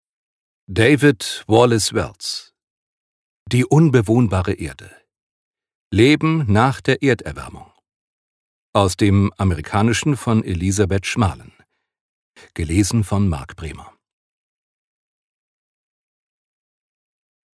Genre: Lesung.